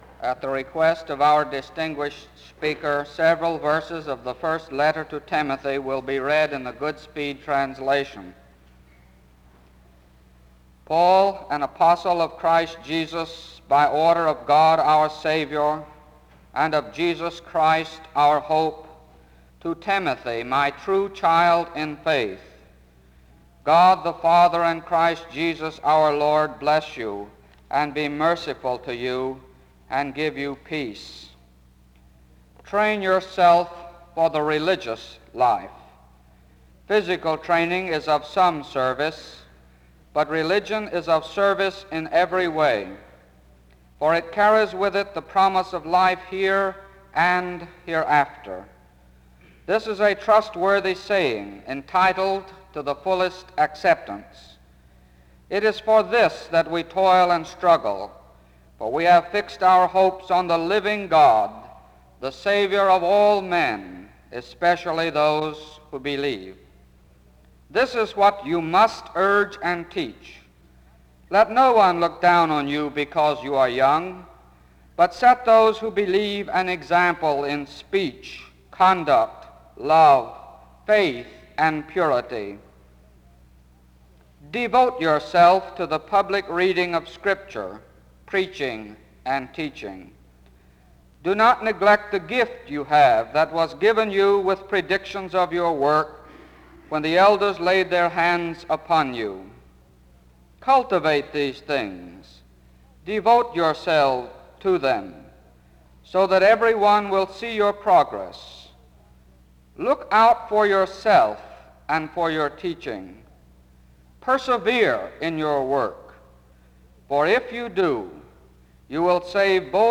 Music plays from 2:21-5:59. An introduction to the speaker is given from 6:12-8:09.
A closing prayer is offered from 55:00-55:24. This is part 2 of a 2 part lecture series.
SEBTS Chapel and Special Event Recordings SEBTS Chapel and Special Event Recordings